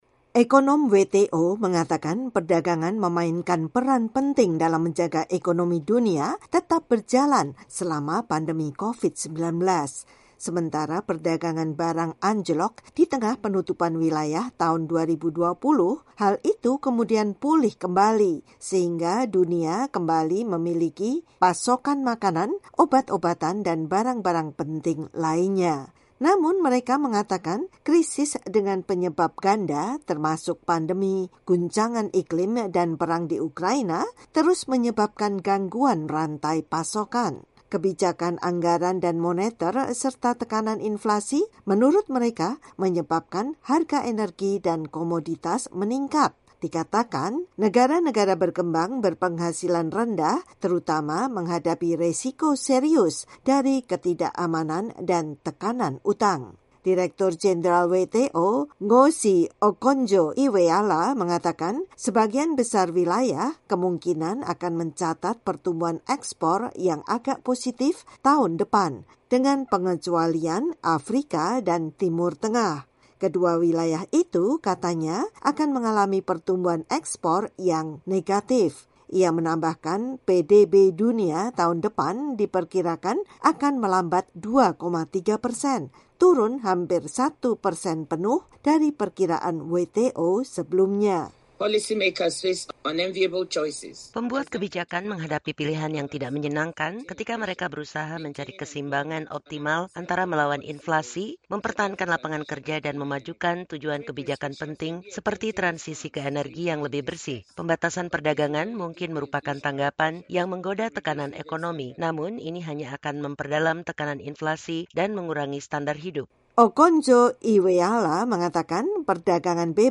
Organisasi Perdagangan Dunia (WTO) memperkirakan pertumbuhan perdagangan dunia akan melambat tajam menjadi satu persen pada 2023, turun dari perkiraan tertinggi 3,5 persen tahun ini. Laporan